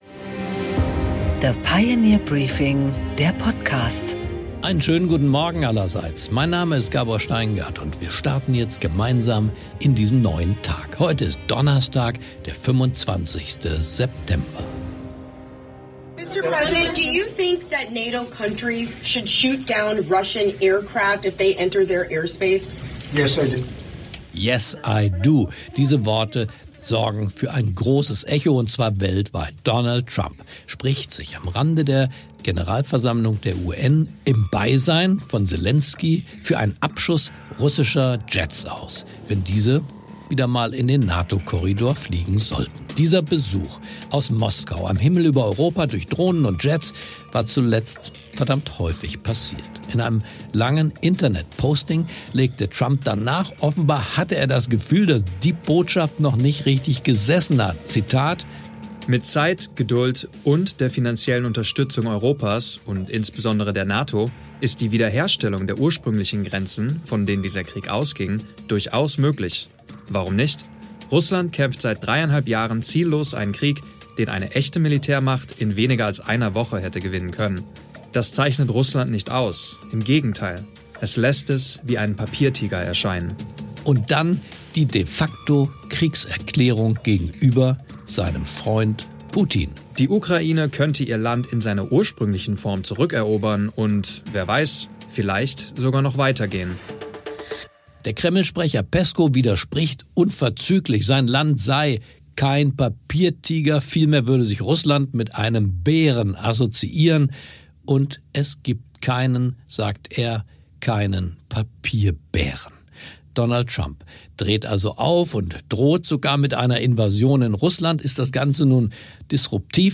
Gabor Steingart präsentiert das Pioneer Briefing
Interview mit Katherina Reiche